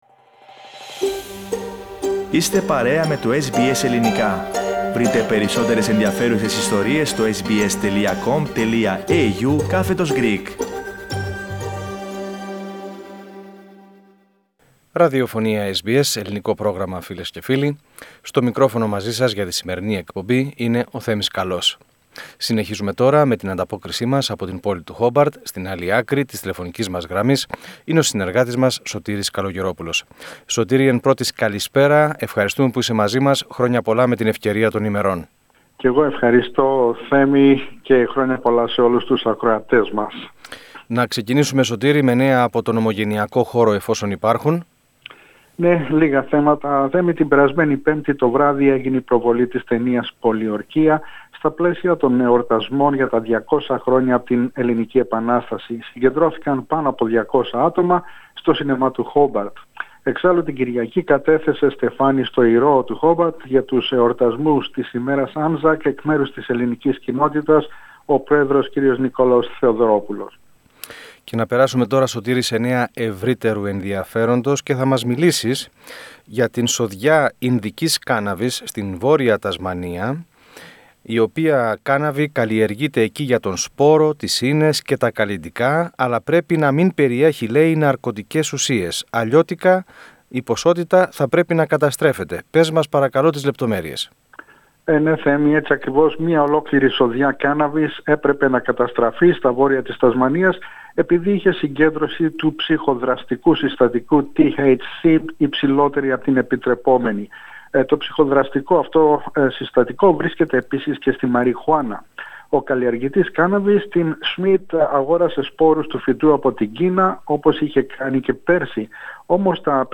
Πατήστε PLAY πάνω στην εικόνα για να ακούσετε την ανταπόκριση για το SBS Greek/SBS Ελληνικά από την Τασμανία.